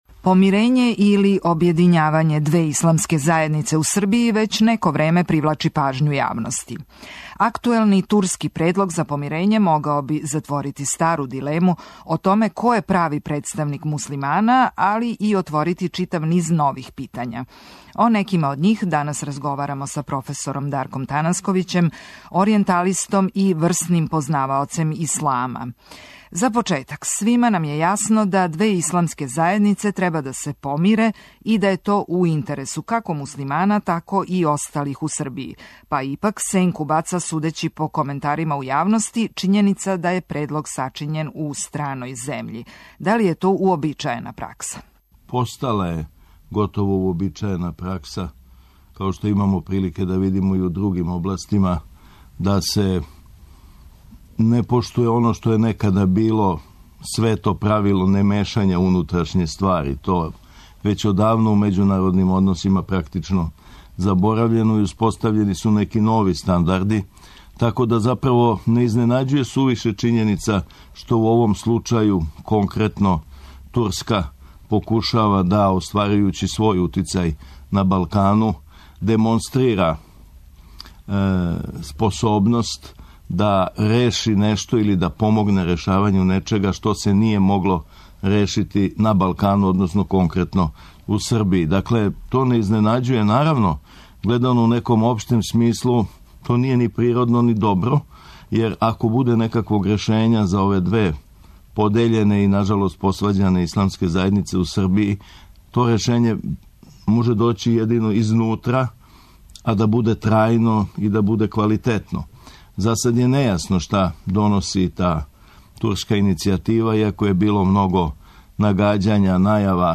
Гост емисије је оријенталиста, проф. Дарко Танасковић.